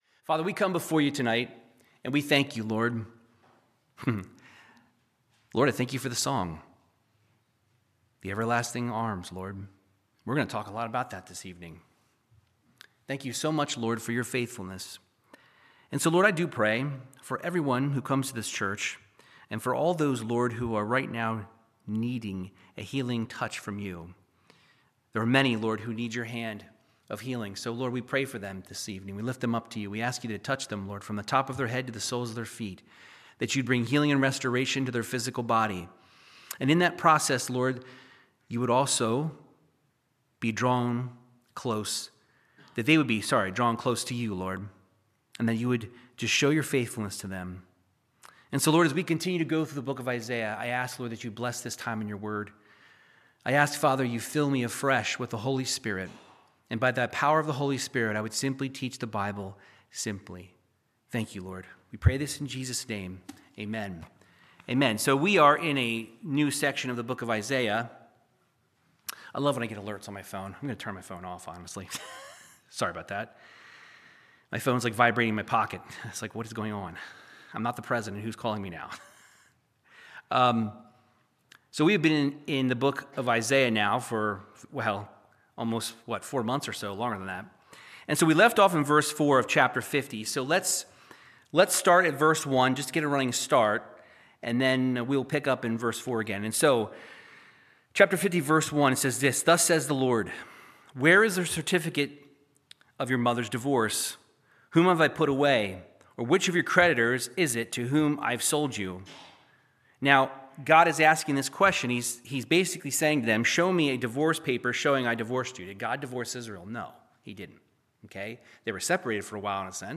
Verse by verse Bible teaching in the book of Isaiah chapters 50-52